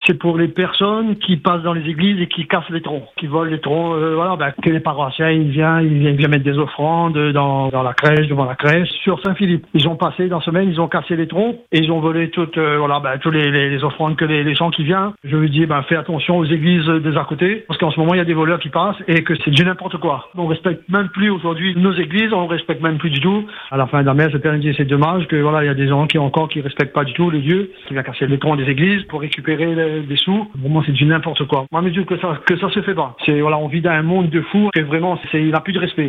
Du côté de Saint-Philippe, un habitant pousse un coup de colère. Il dénonce des vols répétés de troncs d’église, ces boîtes destinées à recueillir l’argent des fidèles.